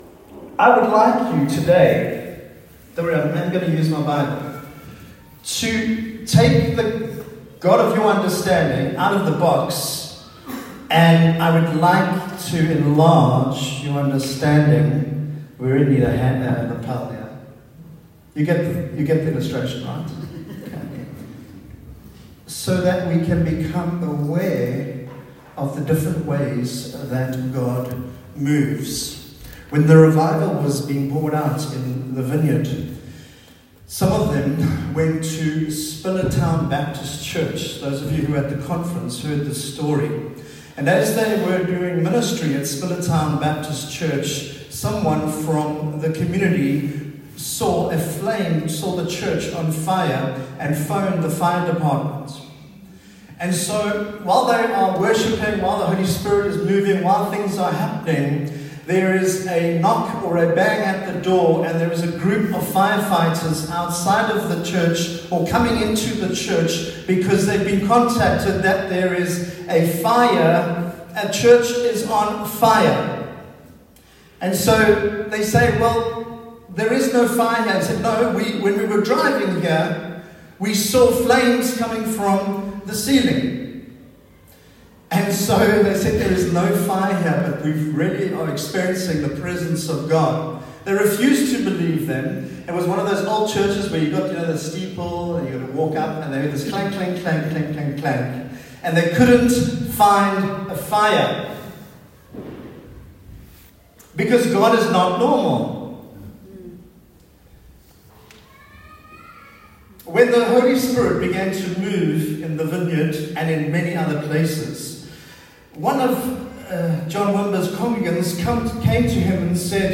Sunday Service – 5 Oct
Sermons